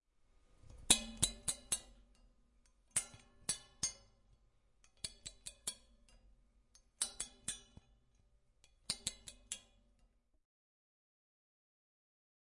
描述：串和干衣机